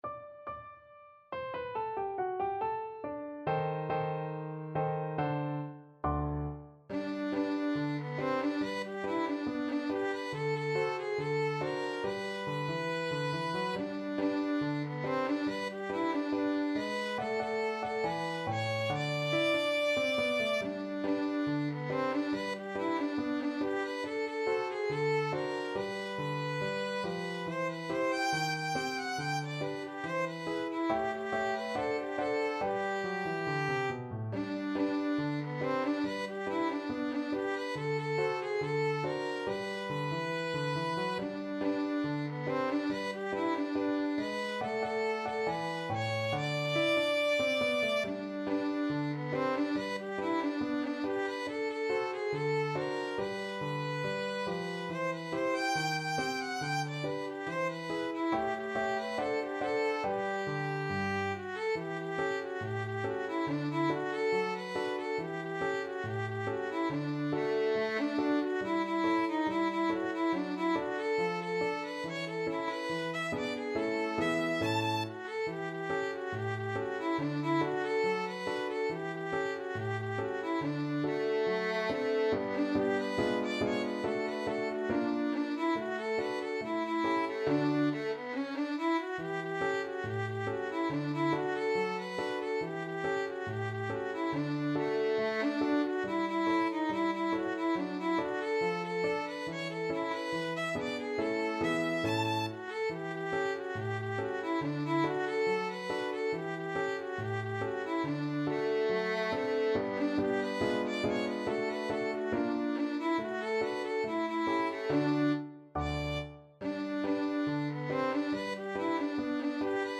Violin
G major (Sounding Pitch) (View more G major Music for Violin )
2/4 (View more 2/4 Music)
Not fast Not fast. = 70
Jazz (View more Jazz Violin Music)